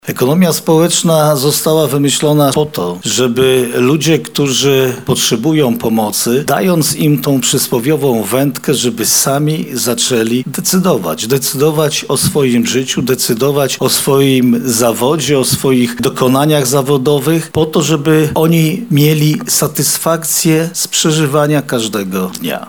Konferencja Regionalnego Ośrodka Polityki Społecznej miała miejsce 3 grudnia 2025 r. Głównym celem rozmów ekspertów zajmujących się rozwojem społecznym i rynkiem pracy była współpraca w zakresie międzyinstytucjonalnym.
Jarosław Stawiarski-podkreśla marszałek województwa lubelskiego Jarosław Stawiarski
Jaroslaw-Stawiarski.mp3